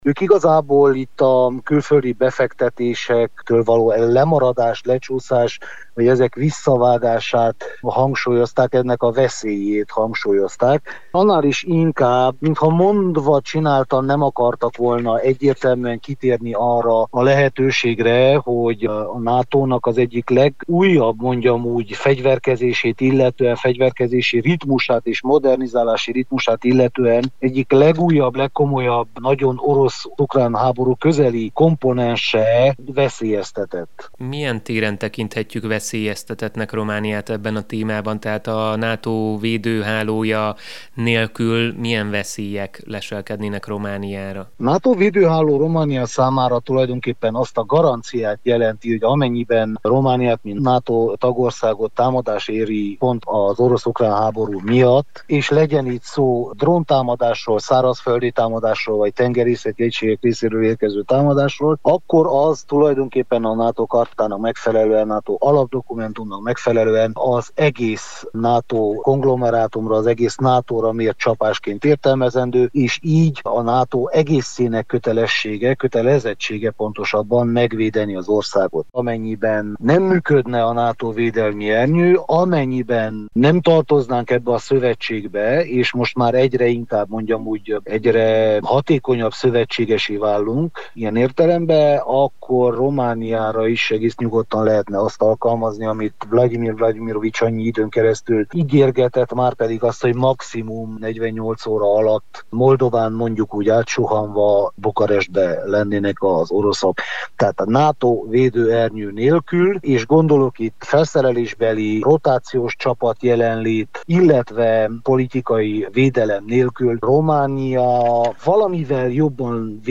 Riporter